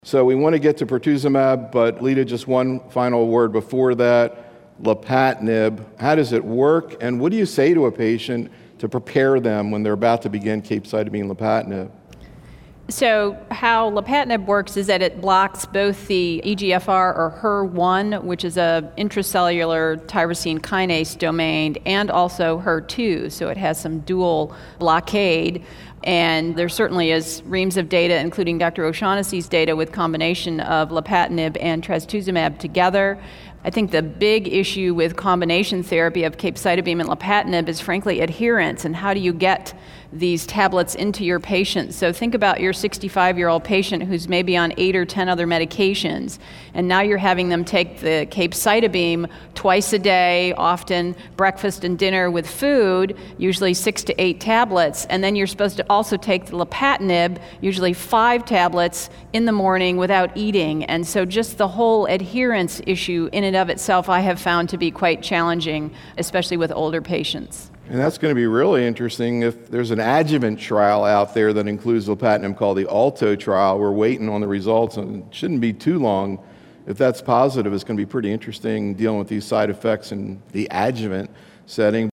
In these audio proceedings from a symposium held in conjunction with the Oncology Nursing Society's 2012 Annual Congress, the invited oncology nursing professionals participating as part of our faculty panel present actual patient cases from their practices, setting the stage for faculty discussion of optimal therapeutic and supportive care strategies in breast cancer.